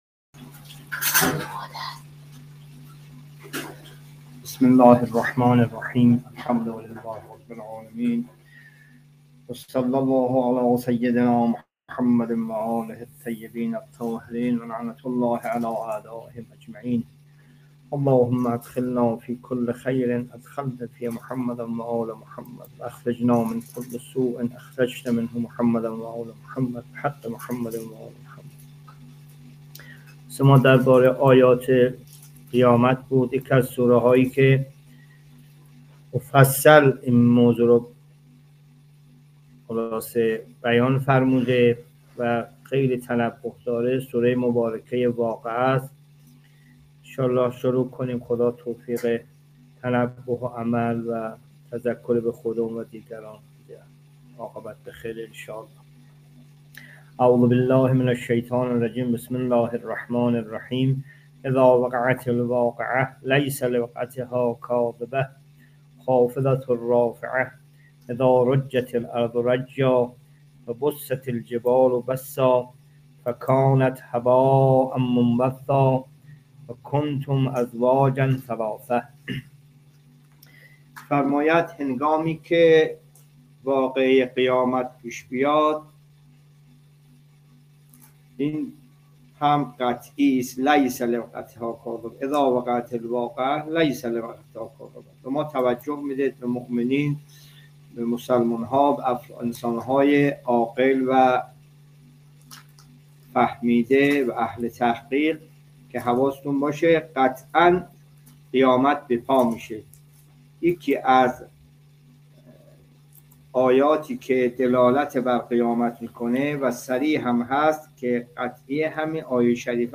جلسه تفسیر قرآن